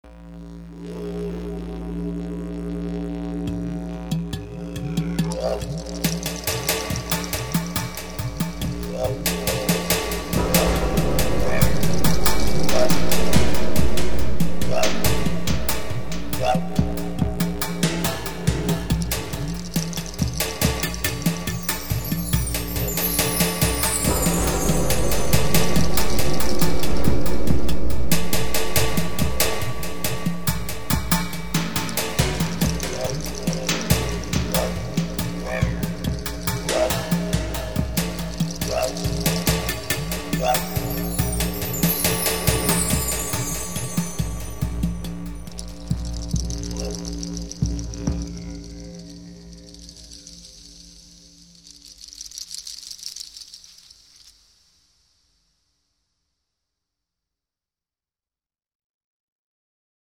Piece featuring a cymbal, a shaker, wind chimes,
a digeridoo, a tom and an Udu drum loop